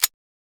grenadepull.wav